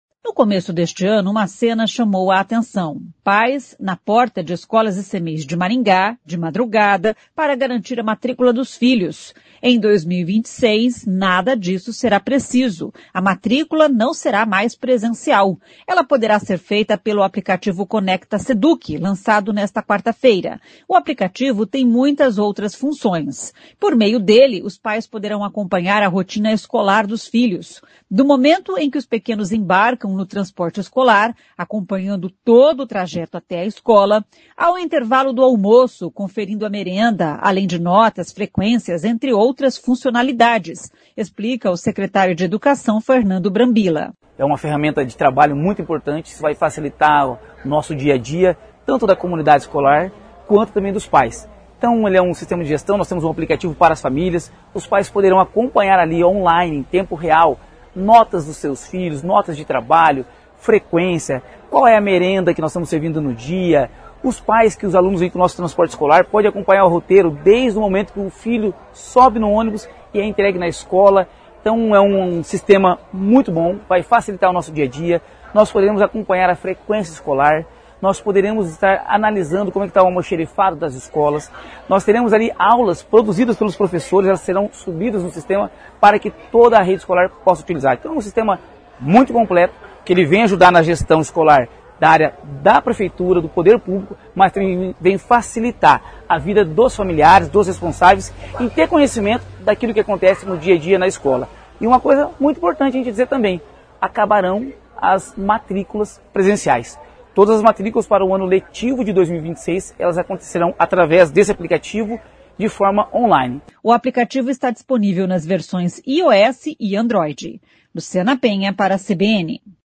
Ao intervalo do almoço, conferindo a merenda; além de notas, frequências; entre outras funcionalidades, explica o secretário de Educação Fernando Brambilla.